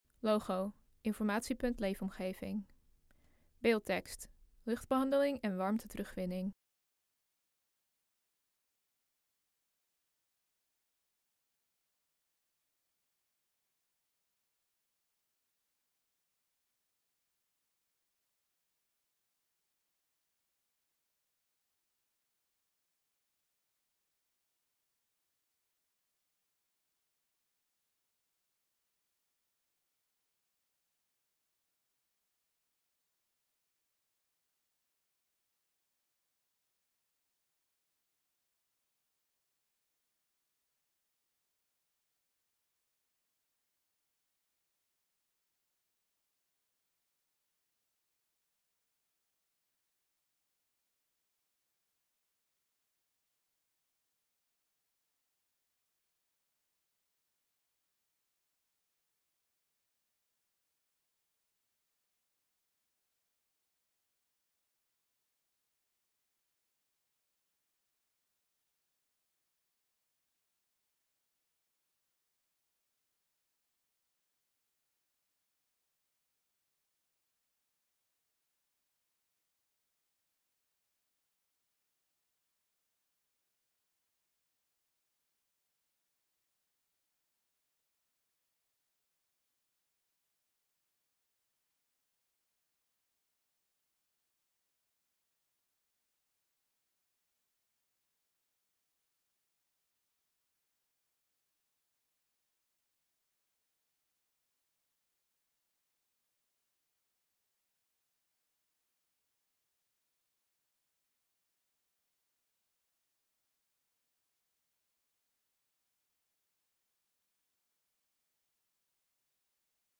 Er zitten drie mannen om een tafel.